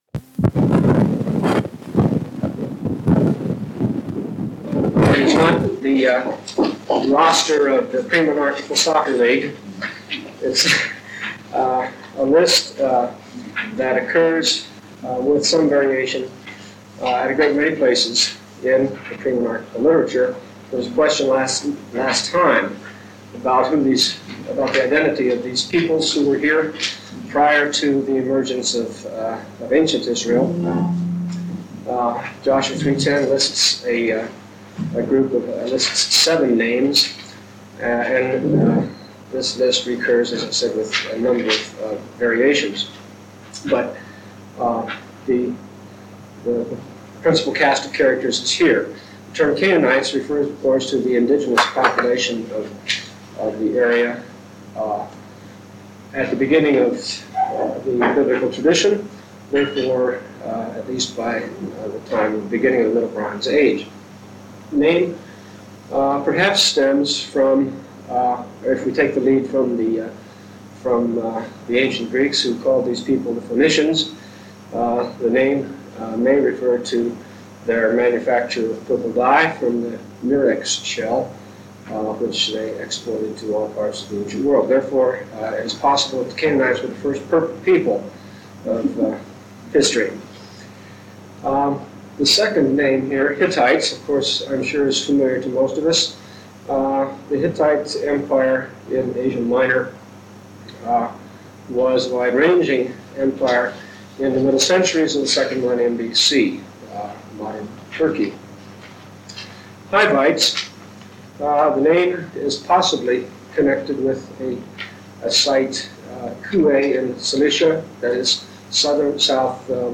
Archaeology and the Book of Joshua and Judges, Lecture #4